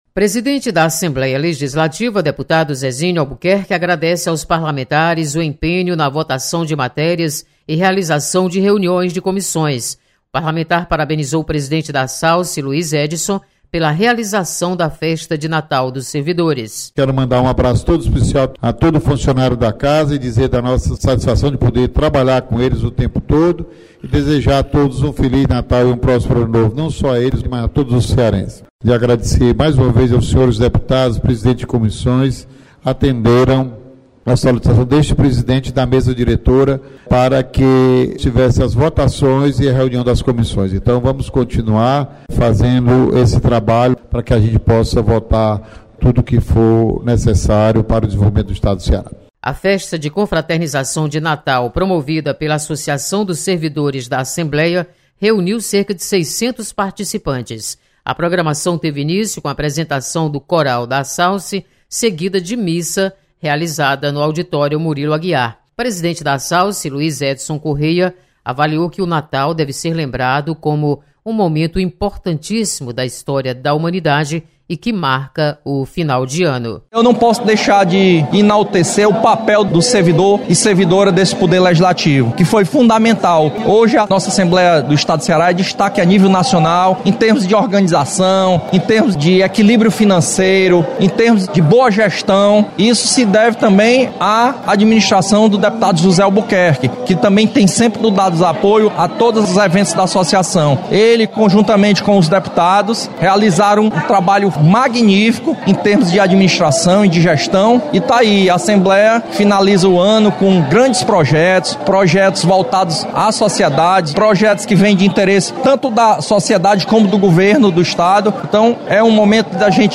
Presidente Zezinho Albuquerque agradece empenho dos parlamentares na votação de matérias e destaca atuação da Associação dos Servidores da Assembleia. Repórter